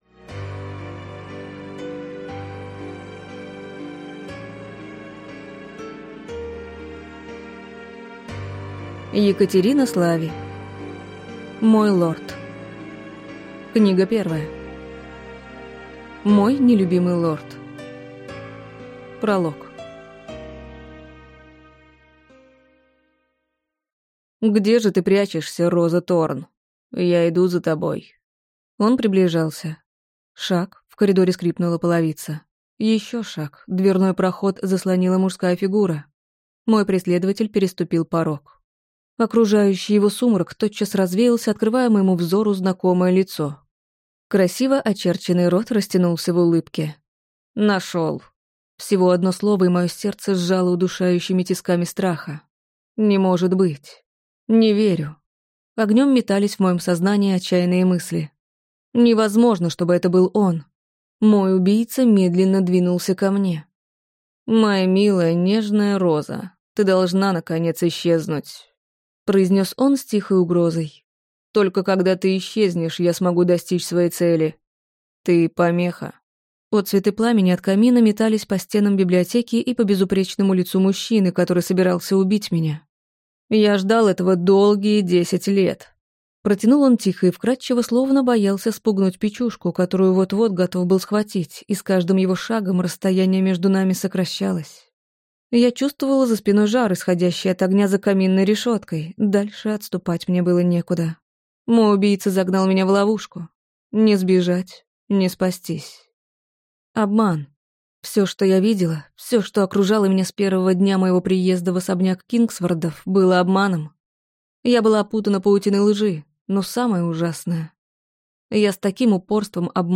Аудиокнига Мой лорд. Книга 1. Мой нелюбимый лорд | Библиотека аудиокниг